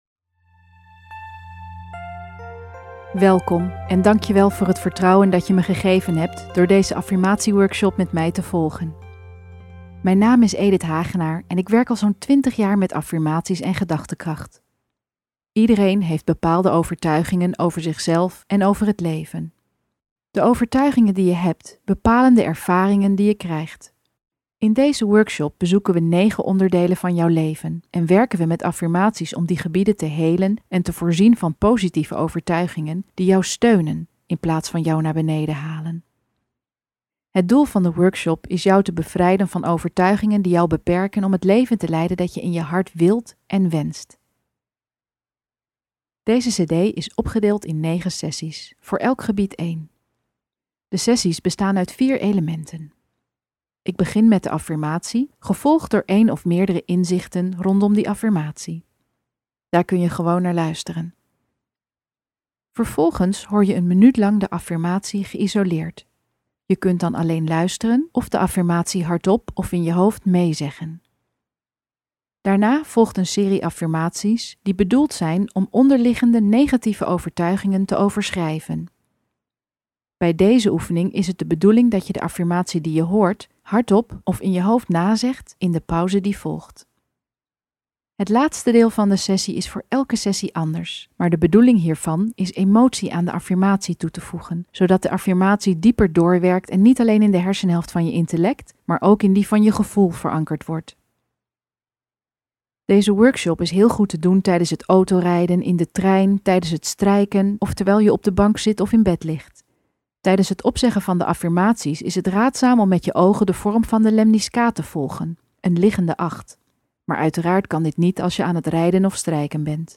Ruim 2,5 uur gesproken tekst en simpele oefeningen die iedereen kan doen.